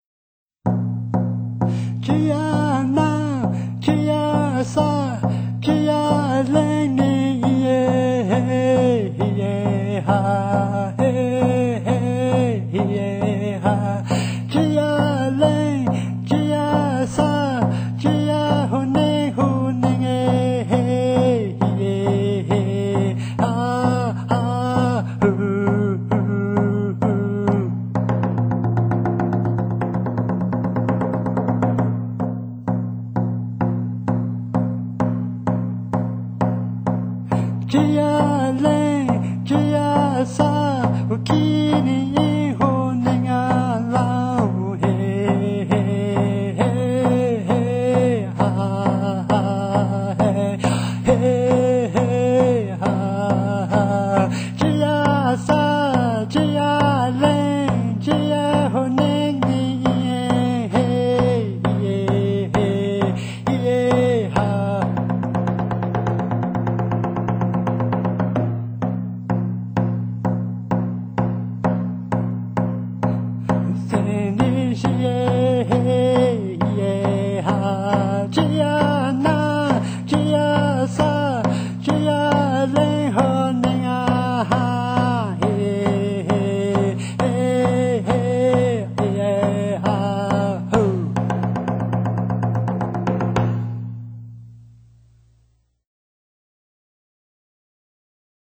在突修尼语自然且深富情感的韵律中，少量而鲜明的吉他声、狂热的键
盘音乐、贝斯、手风琴及打击乐器制造了一种令人深刻的音响！
皮鼓点，更是静谧高远又强有力量！